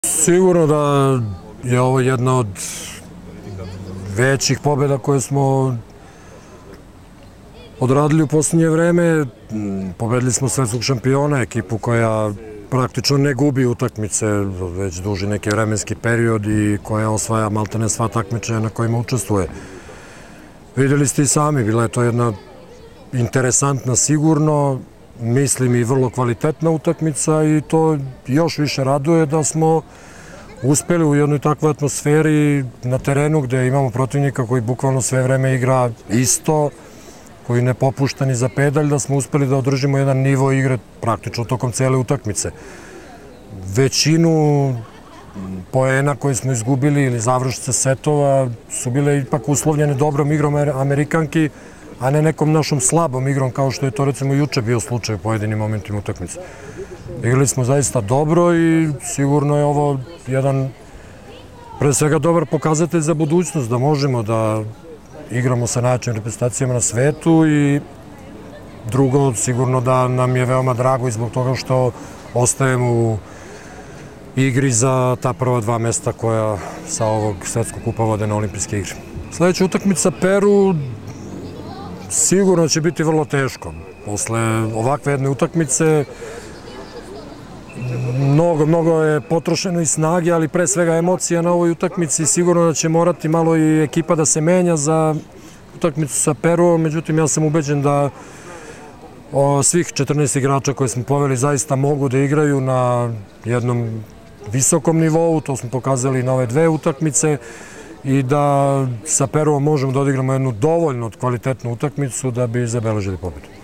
IZJAVA ZORANA TERZIĆA, SELEKTORA SRBIJE